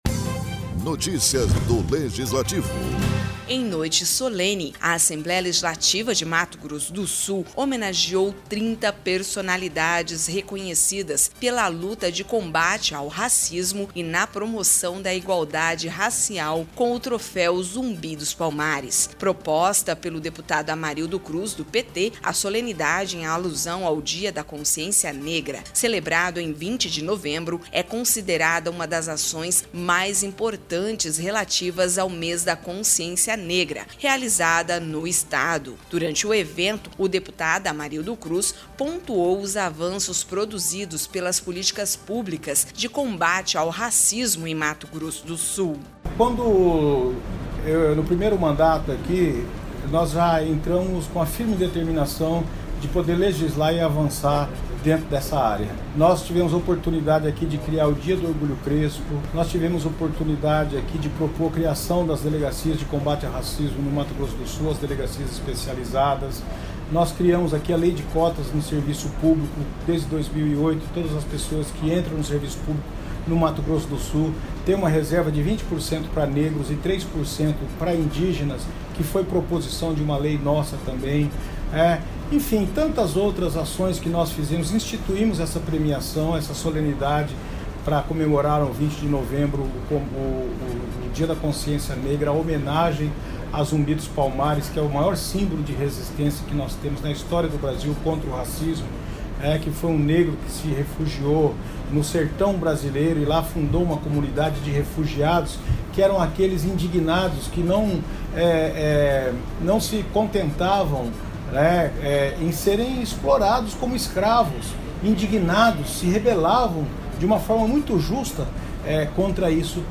Em noite solene, a Assembleia Legislativa de Mato Grosso do Sul (ALEMS) homenageou 30 personalidades reconhecidas pela luta de combate ao racismo e na promoção da igualdade racial com o troféu Zumbi dos Palmares. Proposta pelo Deputado Amarildo Cruz (PT), a solenidade em alusão ao dia da Consciência Negra, celebrado em 20 de novembro é considerada uma das ações mais importantes relativas ao mês da consciência negra realizada no Estado.